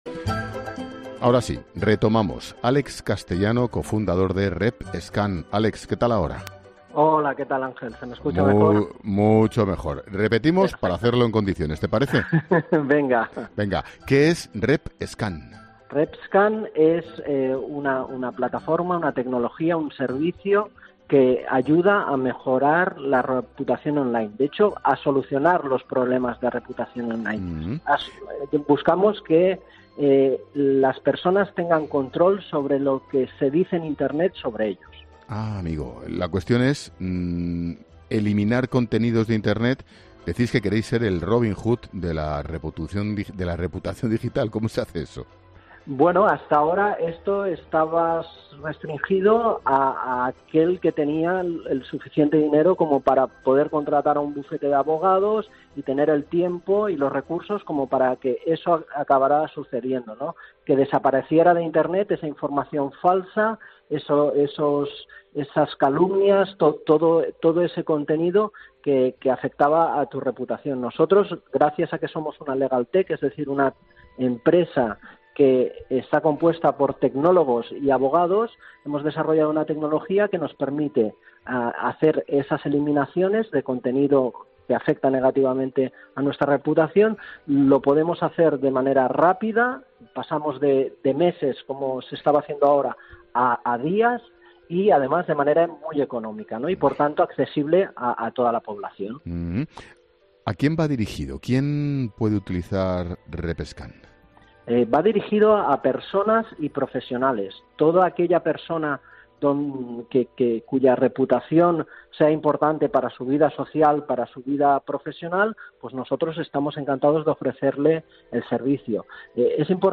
Entrevista_LaCope_LaLinterna.mp3